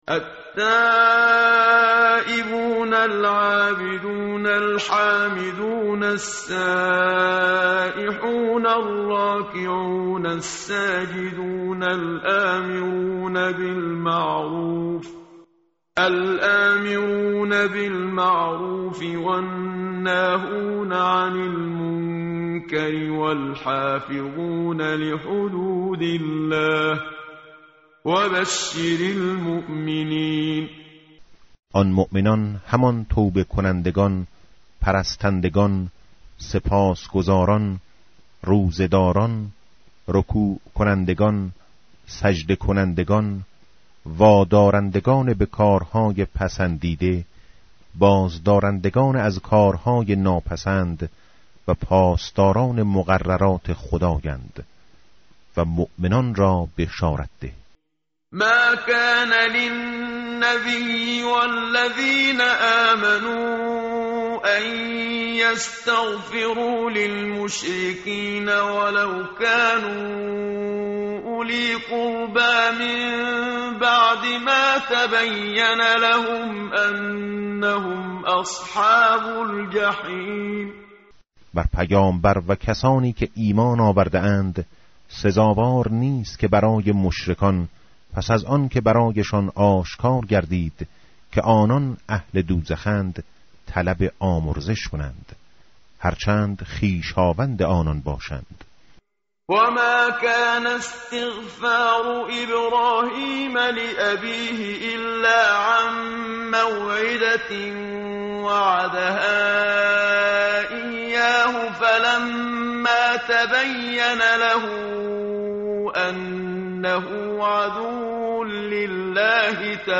متن قرآن همراه باتلاوت قرآن و ترجمه
tartil_menshavi va tarjome_Page_205.mp3